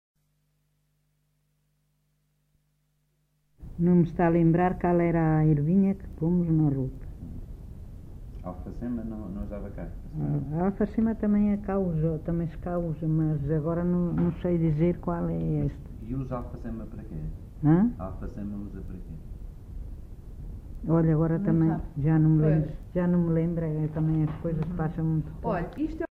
LocalidadeAssanhas (Celorico da Beira, Guarda)